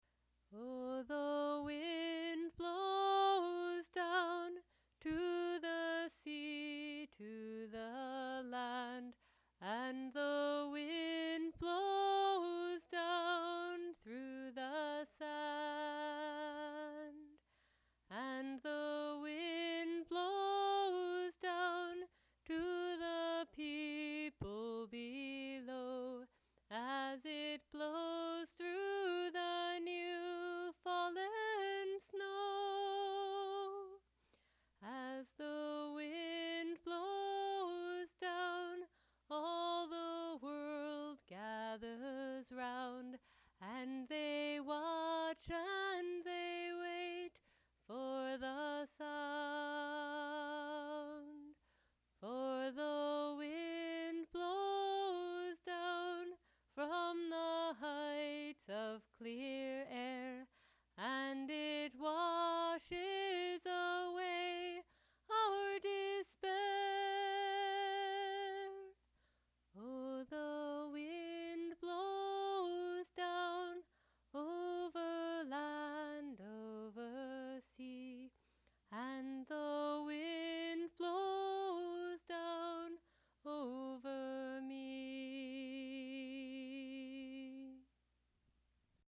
There were some technical difficulties with the gain, for which I'm sorry.